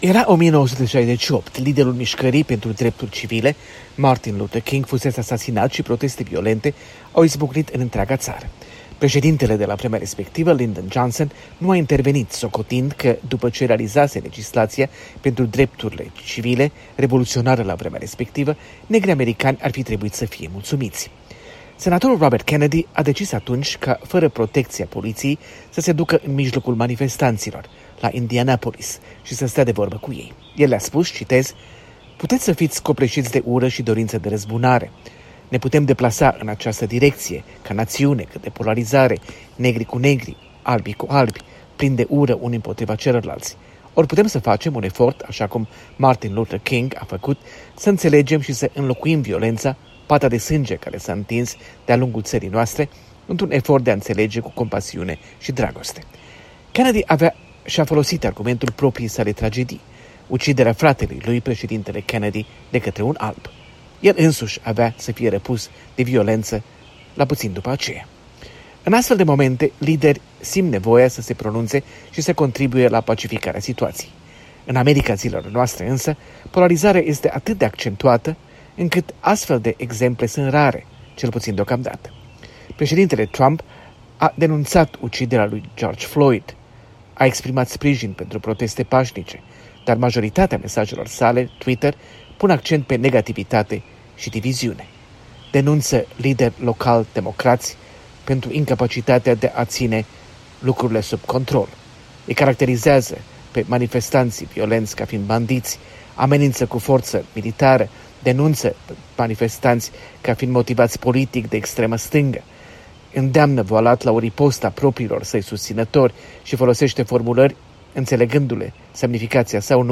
Corespondență de la Washington: arta de a fi conducător politic